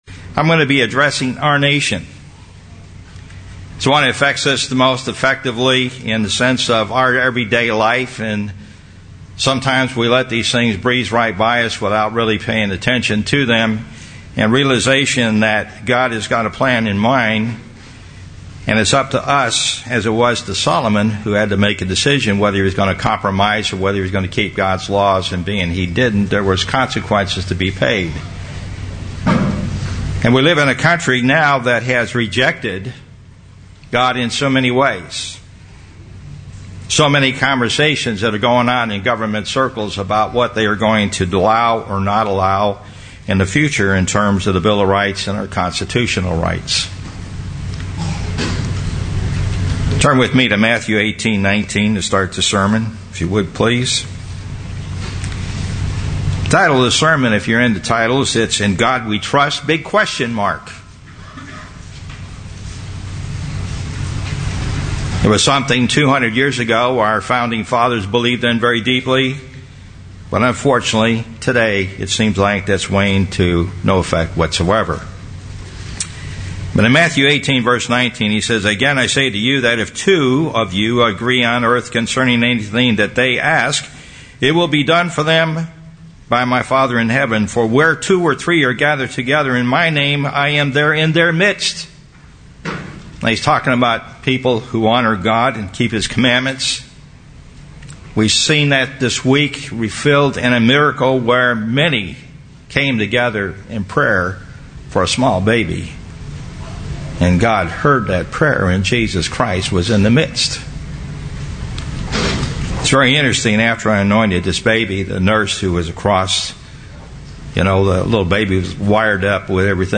UCG Sermon Studying the bible?
Given in Dallas, TX